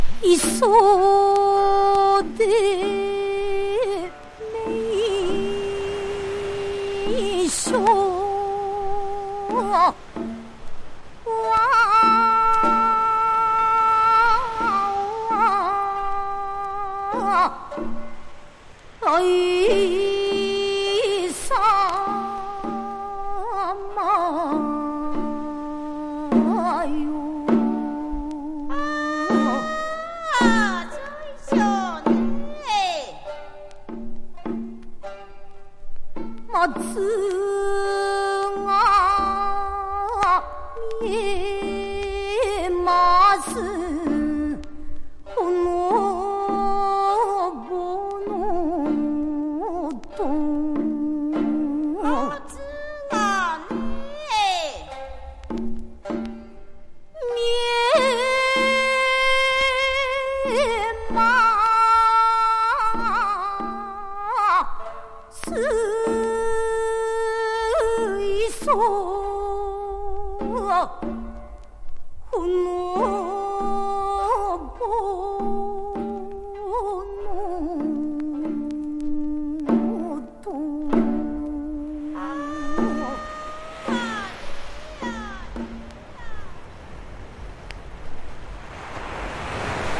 ジャパニーズ・ナイス・レアグルーヴ!!